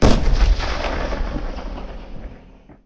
I think the ATGM is slightly hollow sounding, and the artillery is too distant sounding, but they could be useful.